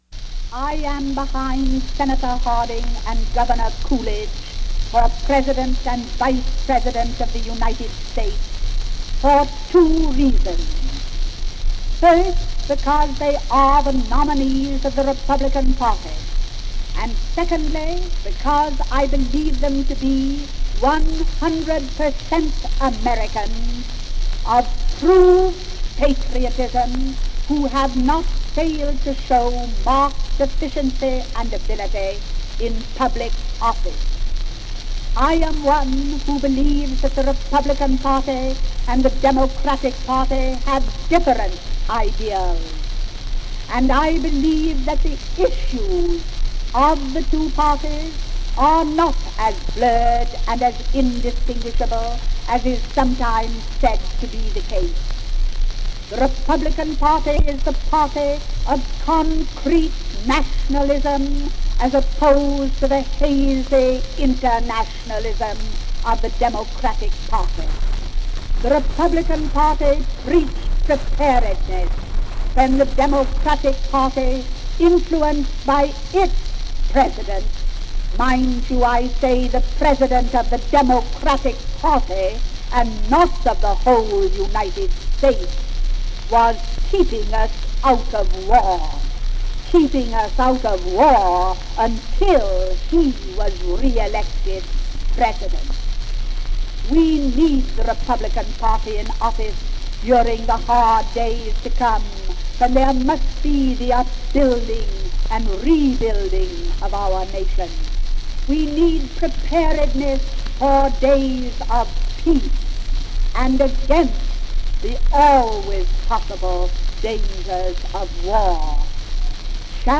Republican campaign speech, 1920